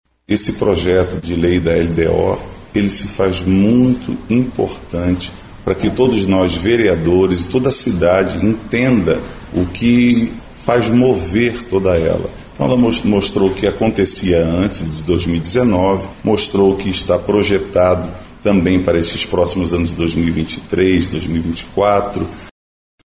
O vereador João Carlos, do Republicanos, destaca o papel deste Projeto de Lei.
Sonora-Joao-Carlos-–-vereador-.mp3